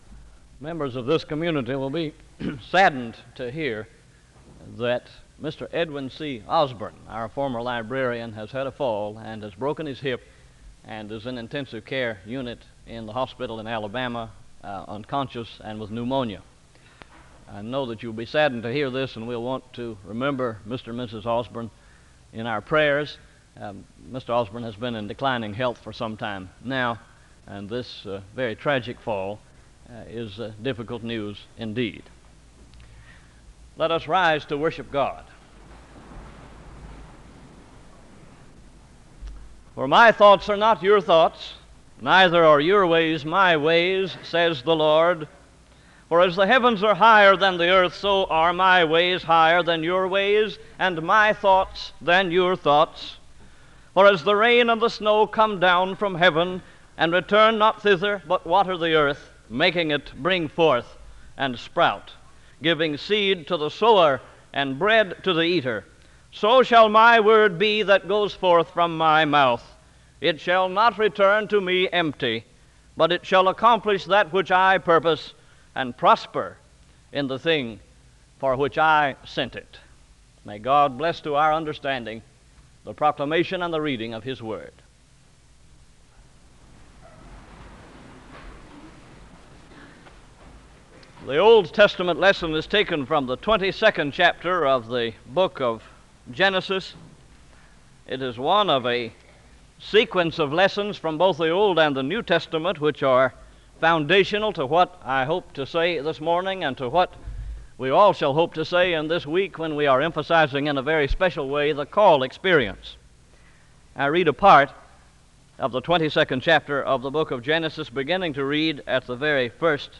The choir sings a song of worship (08:10-12:14).